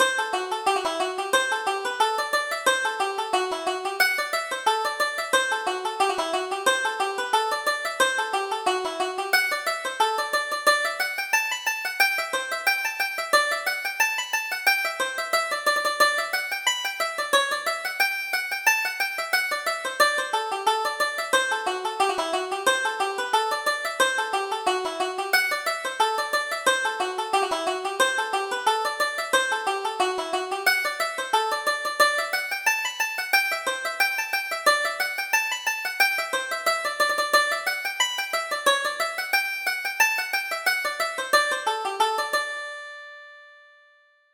Reel: The Bunch of Green Rushes